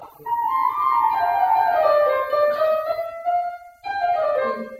[93最后一拍主题再现]所有人都这么弹[在再现前渐慢然后停一下进主题]：